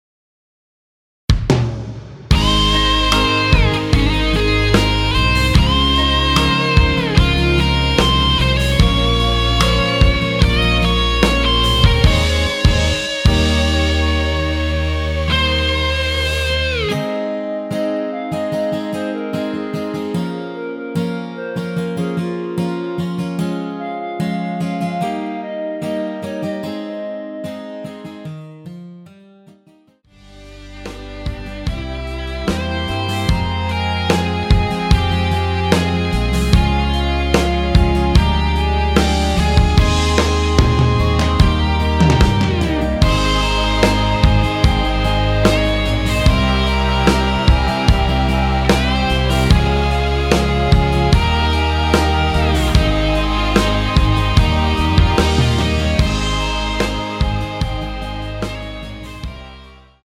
원키에서(+1)올린 멜로디 포함된 MR입니다.
Ab
멜로디 MR이라고 합니다.
앞부분30초, 뒷부분30초씩 편집해서 올려 드리고 있습니다.
중간에 음이 끈어지고 다시 나오는 이유는